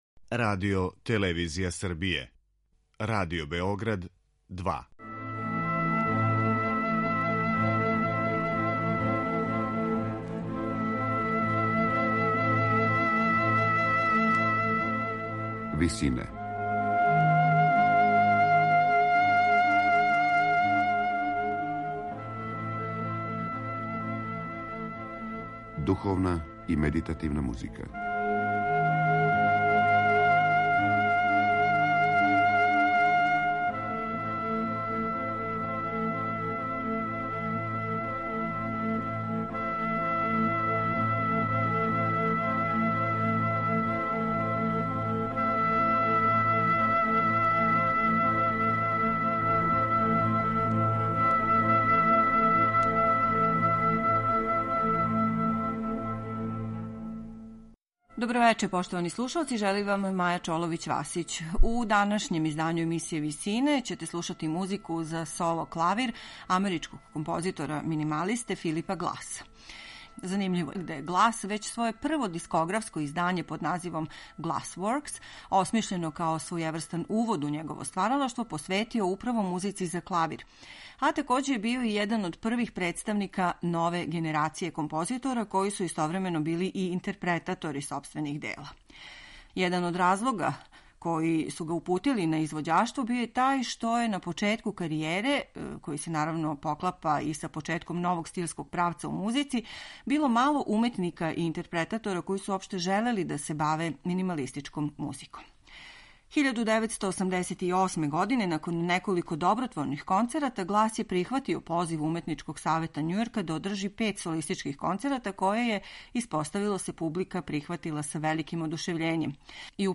минималиста
клавирски циклус